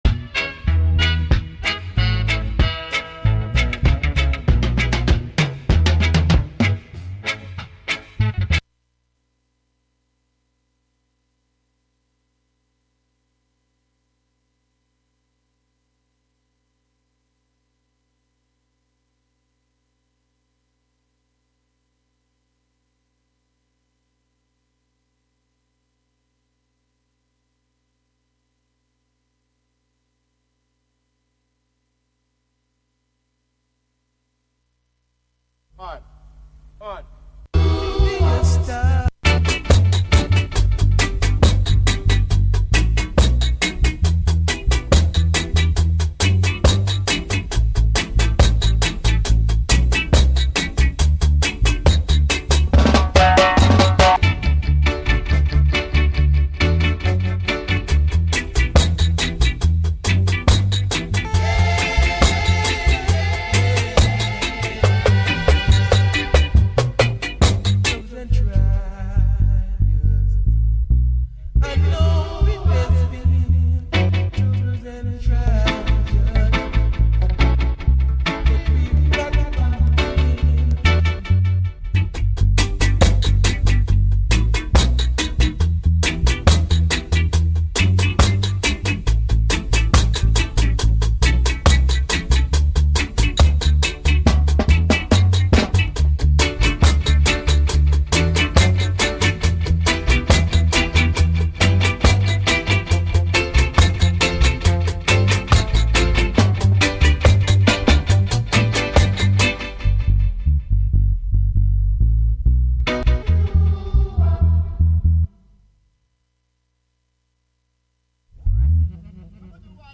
plays a half hour of 1930s and 1940s music, with an occasional foray into other genres.